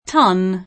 vai all'elenco alfabetico delle voci ingrandisci il carattere 100% rimpicciolisci il carattere stampa invia tramite posta elettronica codividi su Facebook ton [ingl. t 9 n ] s. m. (in it.) metrol.; pl. (ingl.) tons [ t 9 n @ ] — italianizz. ton [ t 0 n ], inv.